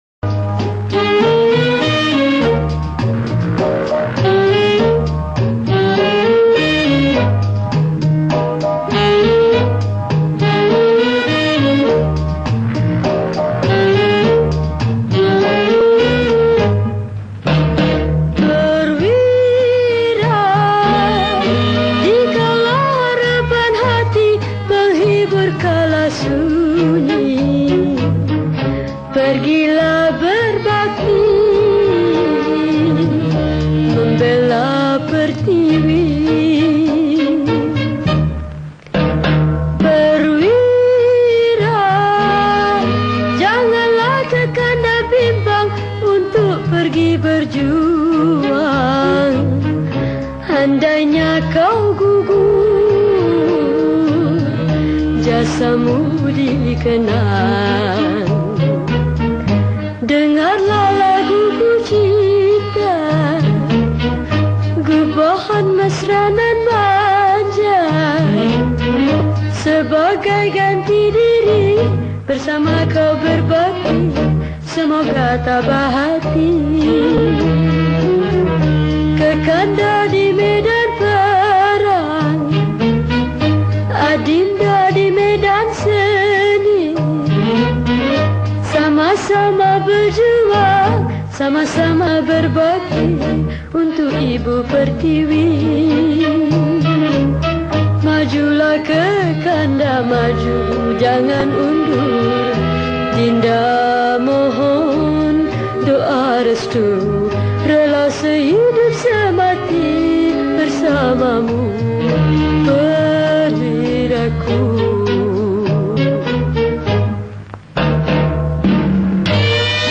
Patriotic Songs
Solo Recorder